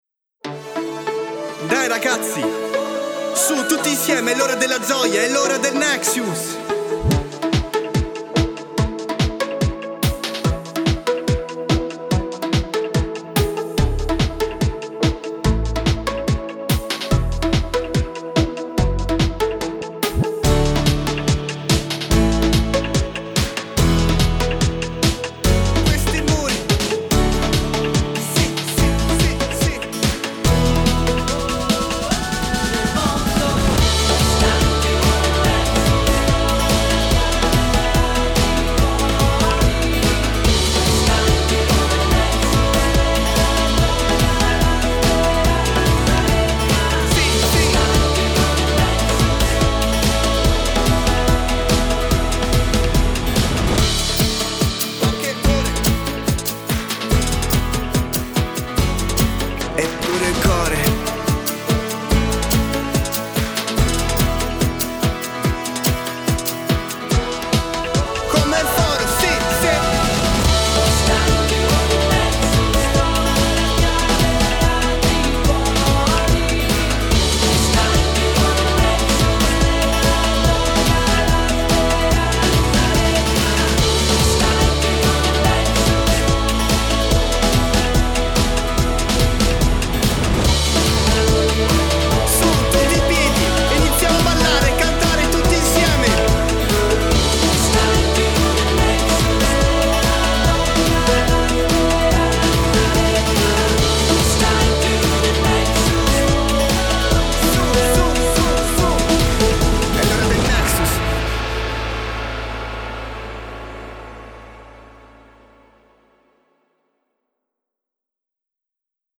BASE File audio BASE della canzone